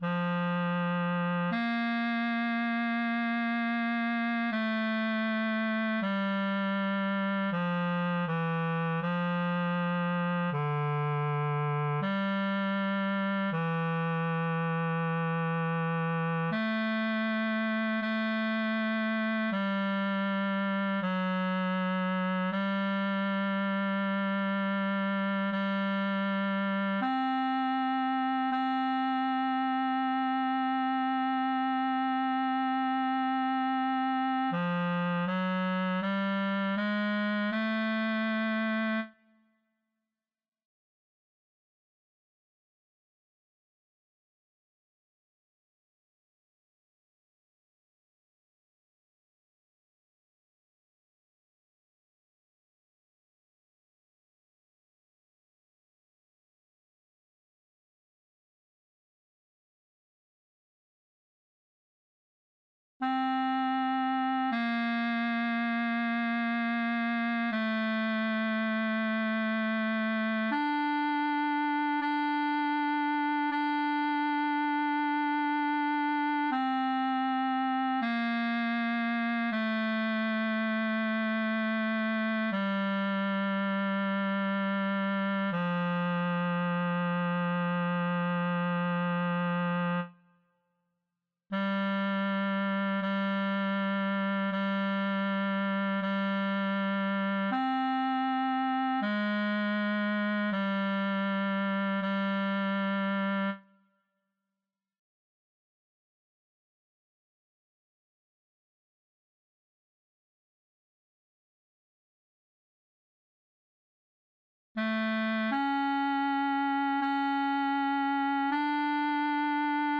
tenor, bas, cor mixt